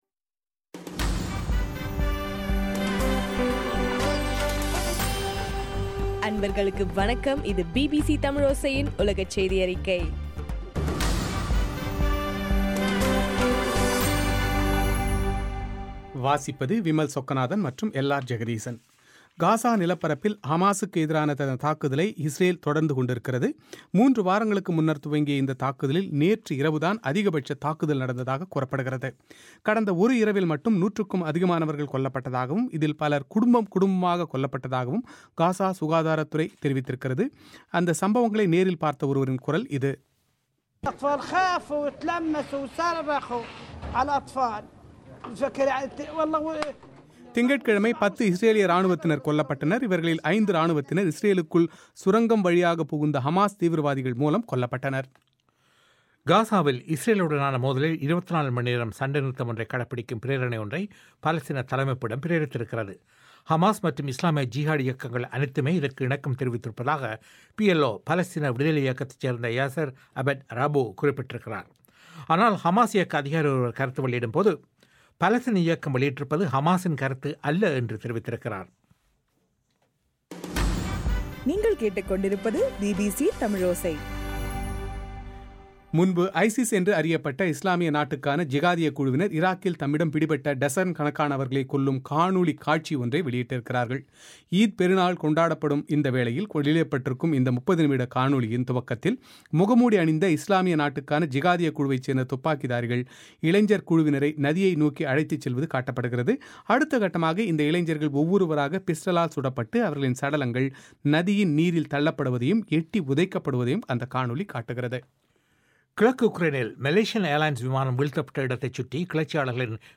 இன்றைய ( ஜூலை 29) பிபிசி தமிழோசை உலகச் செய்தி அறிக்கை